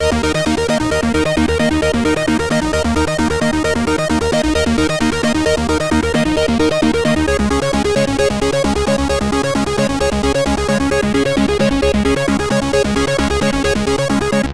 How about a nice distorted bassline in the background?
Track3 should control the Cut-Off parameter of the distorted bass.
...but lower the velocity on LayerB for a better rhythmic feeling: